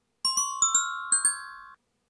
Guide des carillons